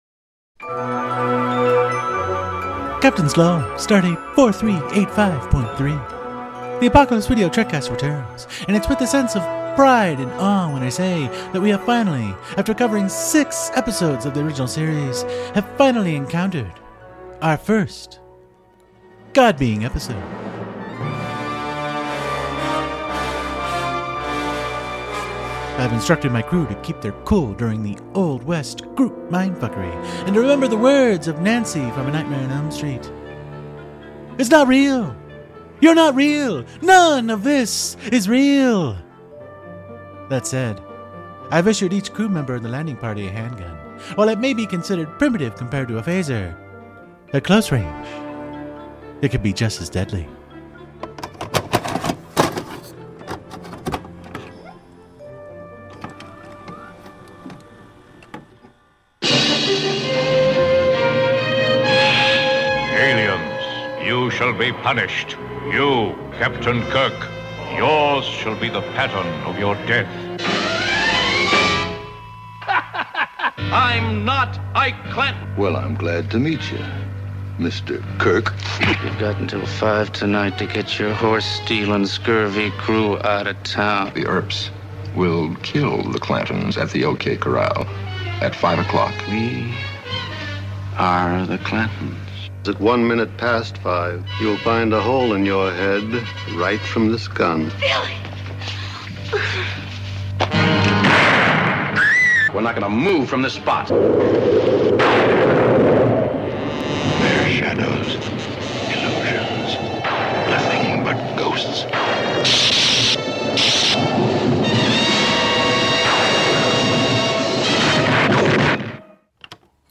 (insert dramatic musical sting)